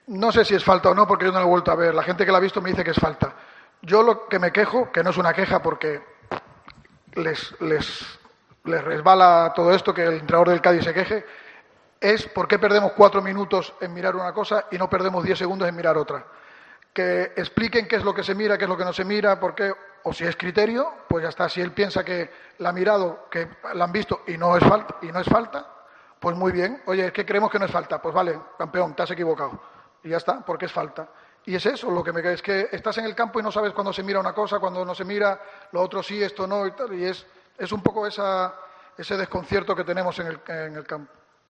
Álvaro Cervera, entrenador del Cádiz, sobre la aplicación del VAR